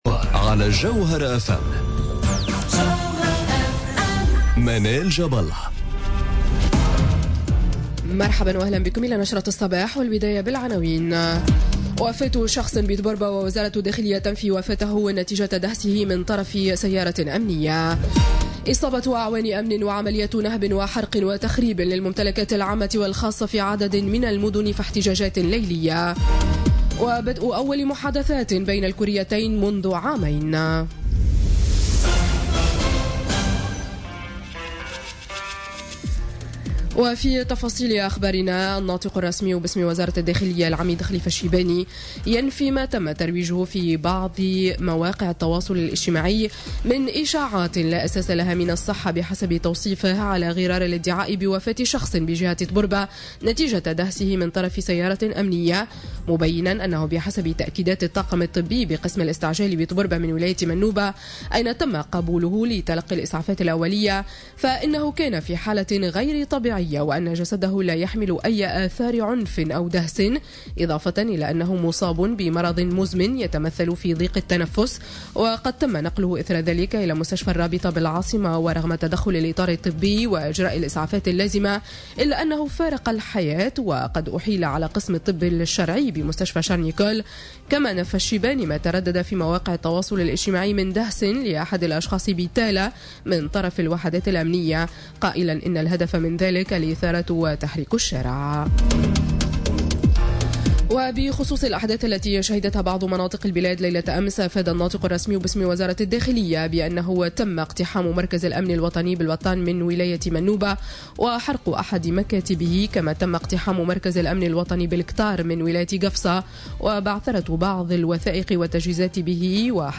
Journal Info 07h00 du mardi 9 janvier 2018